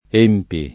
%mpi], il ger. empiendo [